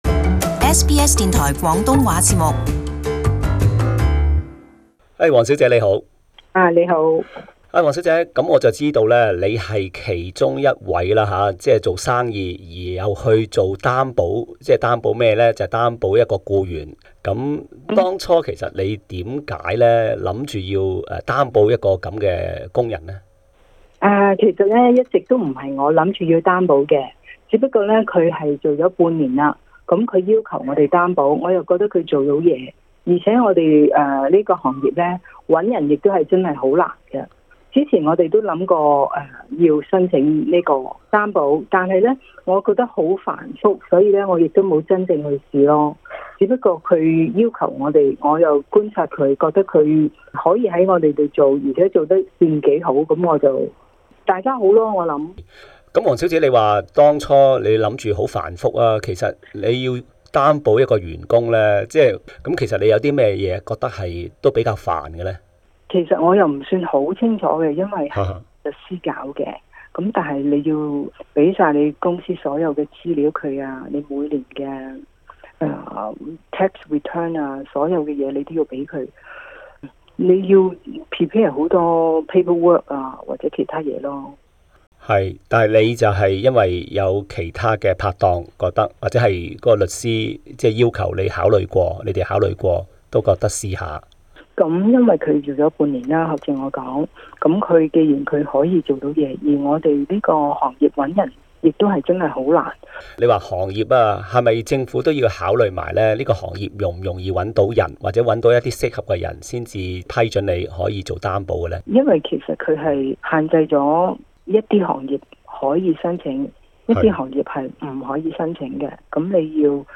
【社區專訪】 僱主擔保海外技術僱員有何考慮?